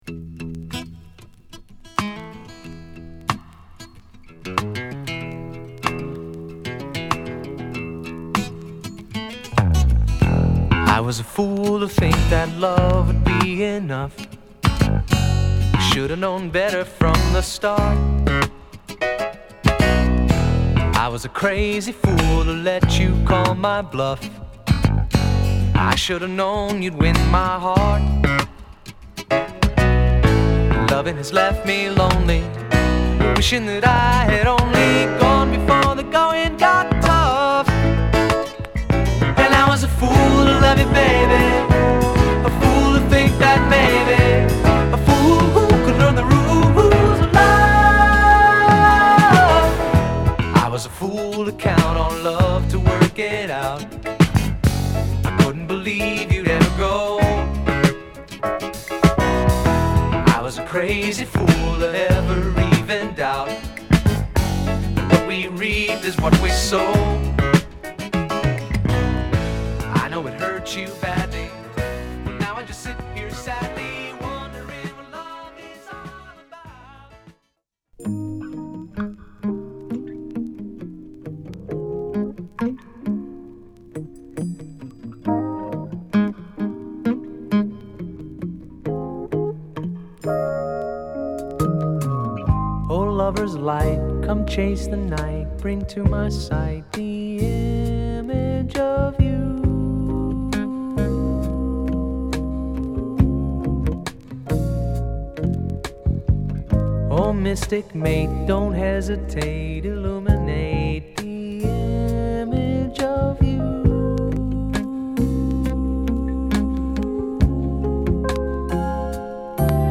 爽やかなフュージョンとフォークが出会ったリラクシンな曲を披露！